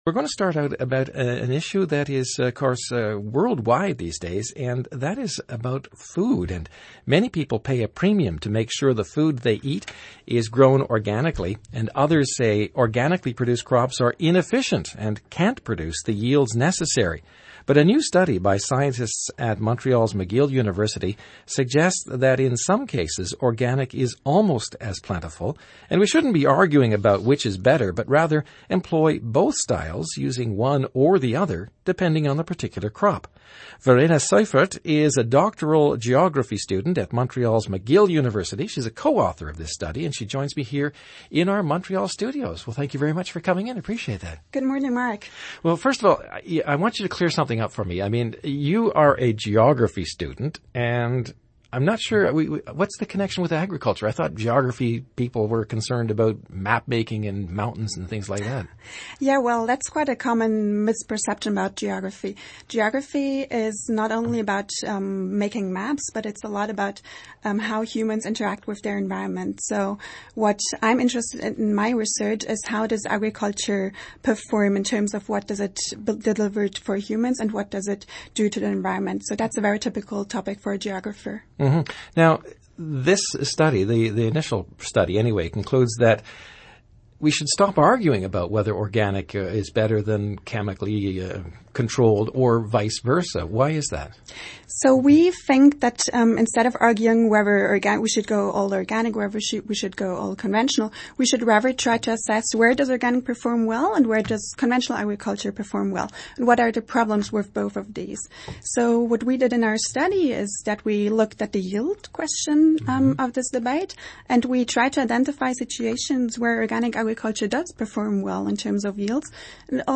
Audio Interviews & Podcasts